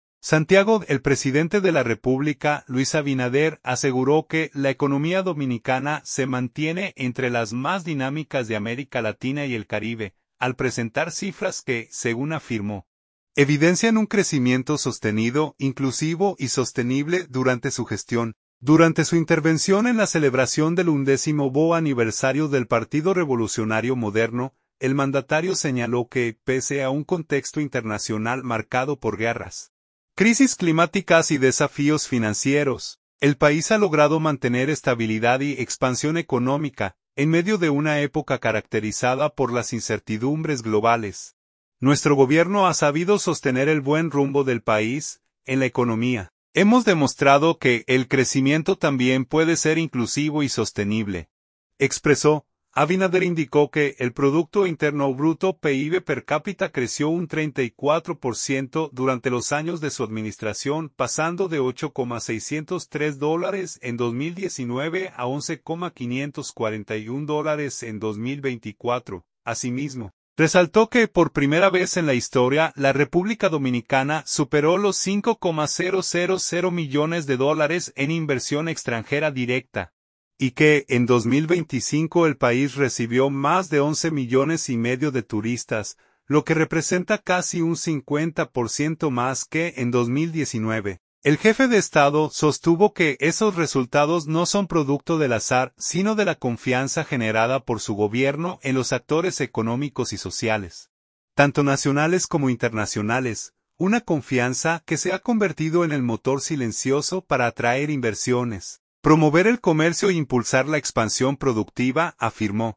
Durante su intervención en la celebración del 11vo aniversario del Partido Revolucionario Moderno, el mandatario señaló que, pese a un contexto internacional marcado por guerras, crisis climáticas y desafíos financieros, el país ha logrado mantener estabilidad y expansión económica.